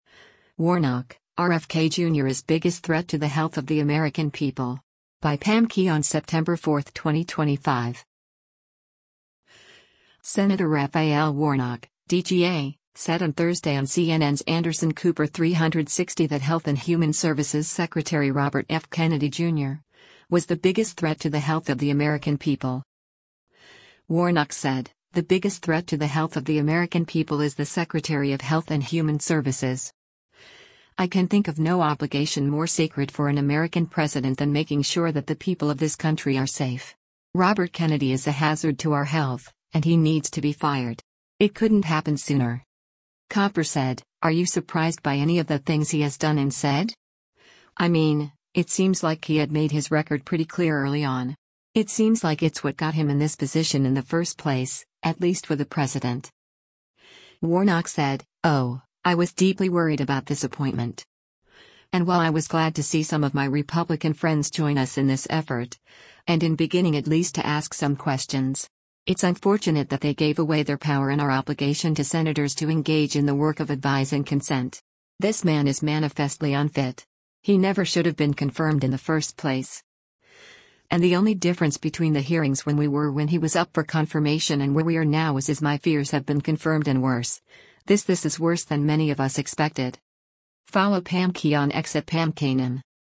Senator Raphael Warnock (D-GA) said on Thursday on CNN’s “Anderson Cooper 360” that Health and Human Services Secretary Robert F. Kennedy, Jr., was the “biggest threat to the health of the American people.”